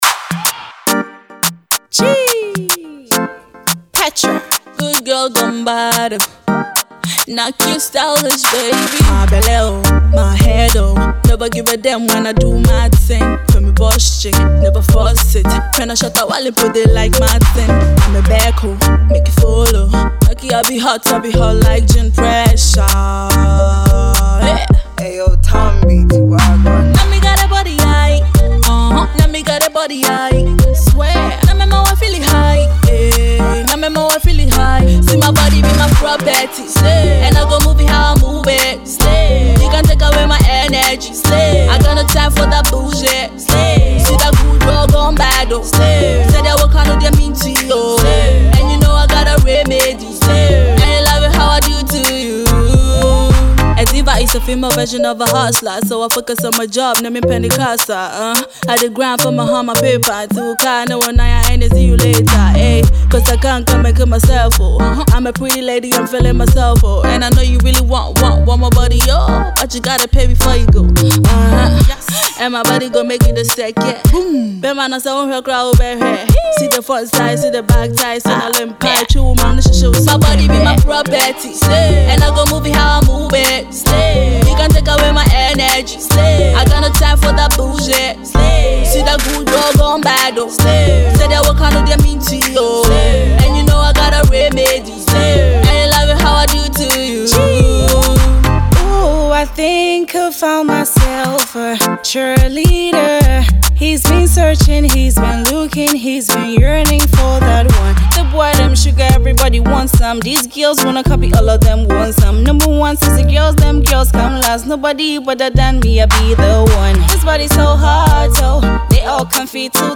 dancehall songstress